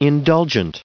1914_indulgent.ogg